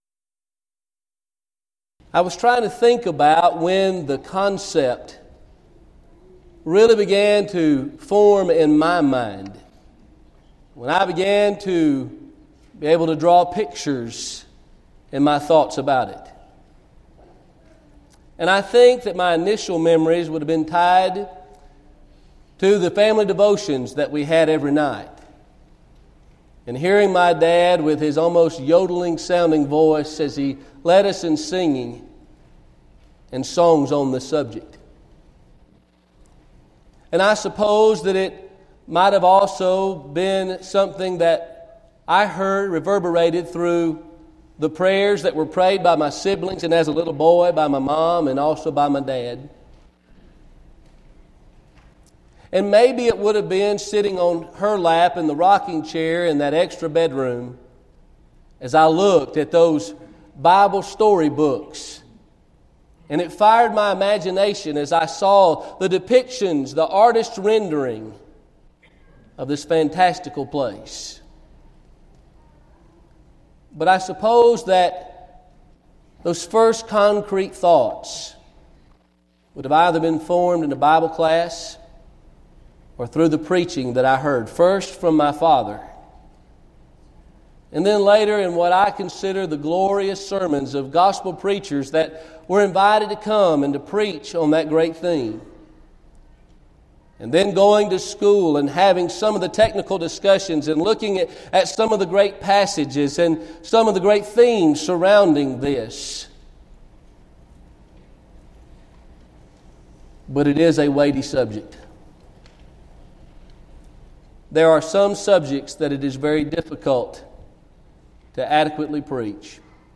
Event: 2014 Focal Point Theme/Title: Preacher's Workshop
lecture